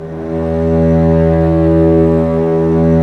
Index of /90_sSampleCDs/Roland L-CD702/VOL-1/STR_Vcs Bow FX/STR_Vcs Sordino